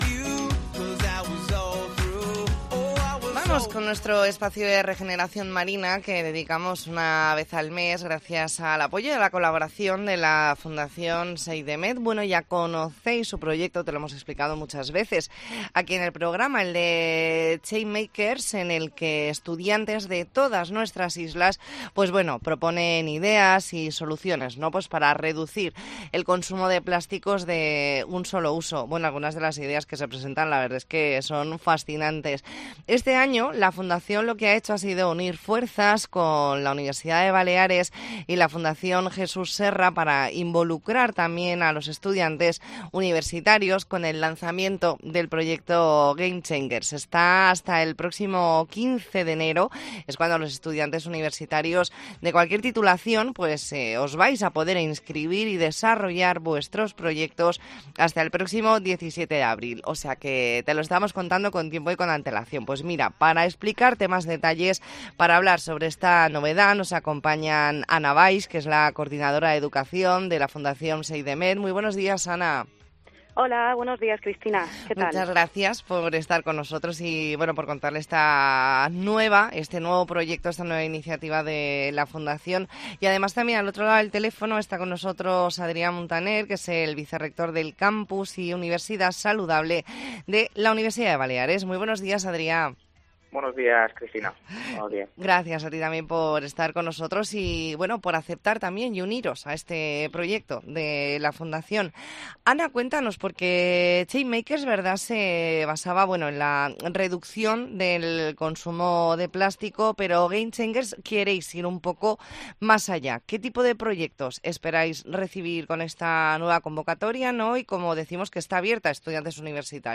Entrevista en La Mañana en COPE Más Mallorca, martes 21 de noviembre de 2023.